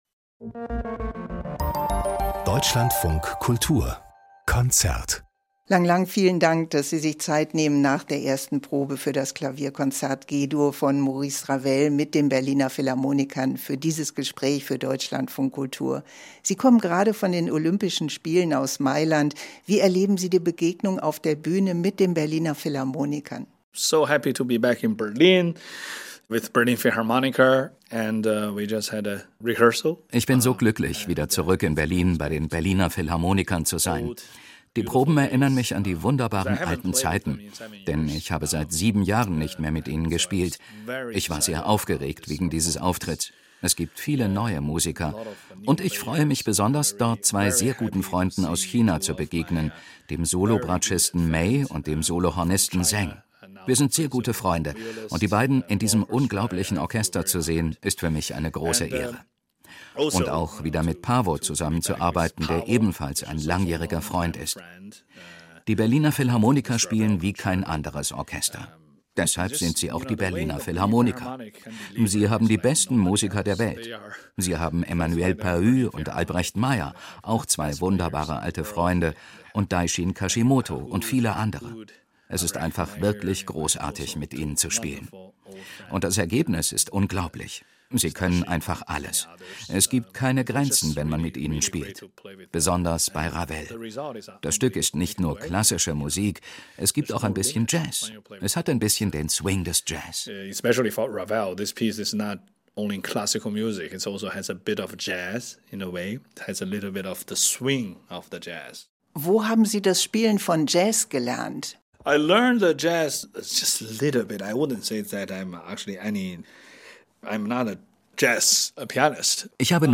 Konzertpause - Lang Lang im Gespräch